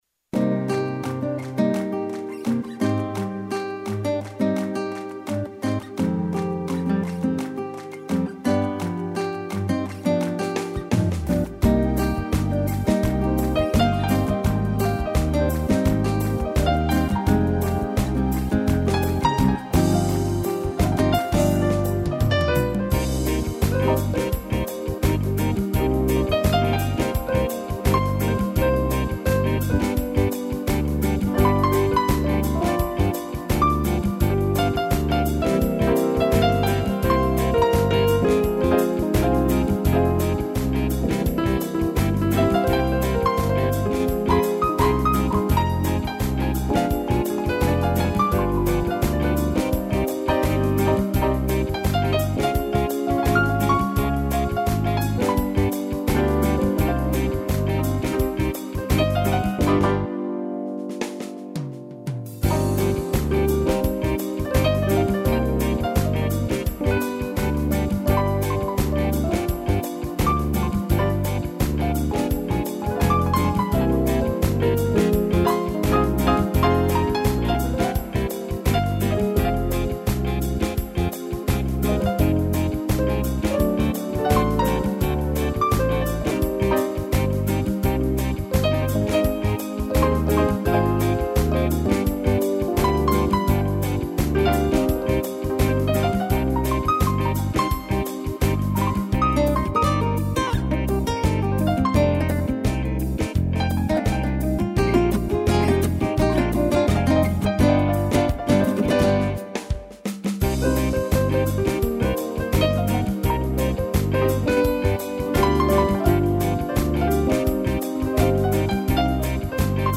piano, sax e cuíca